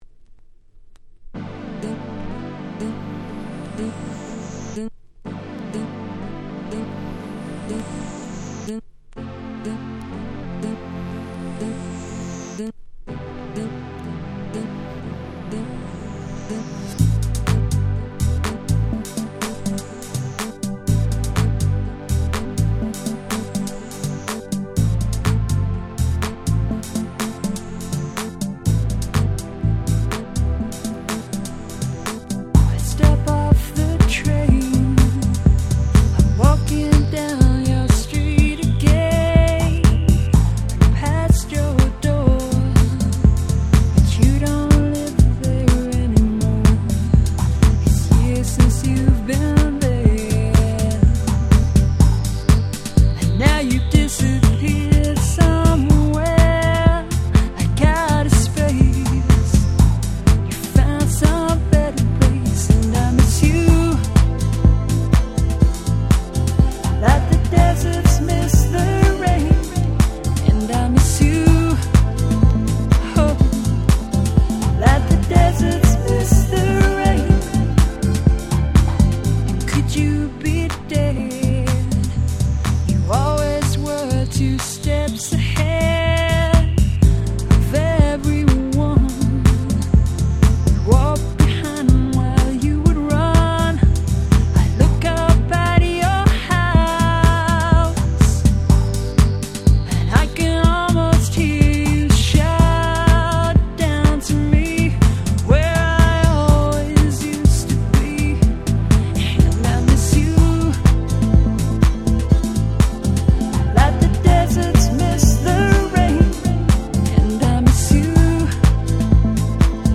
94' Super Hit R&B / Pops / Vocal House !!